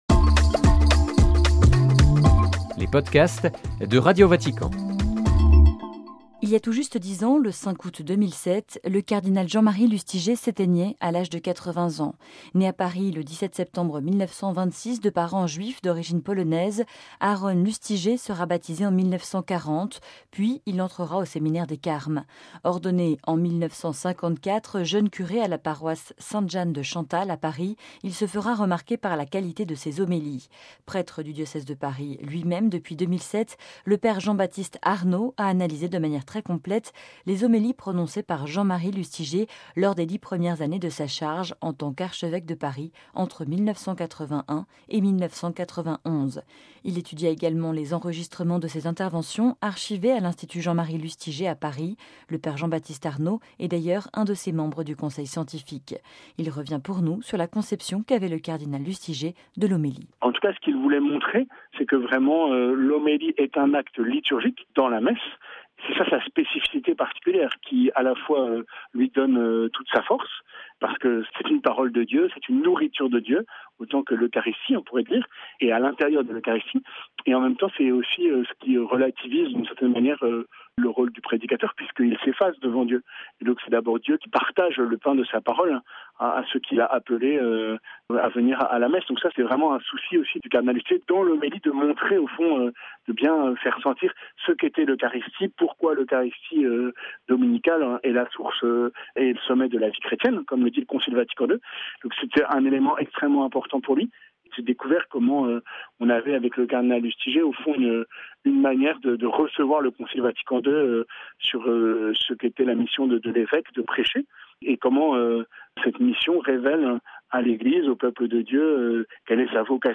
(RV) Entretien